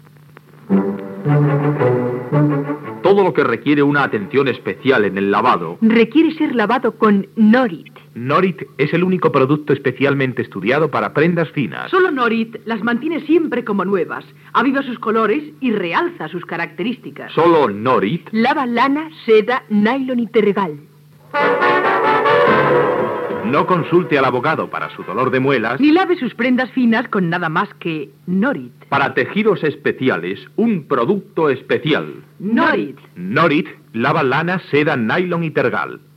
Anunci Norit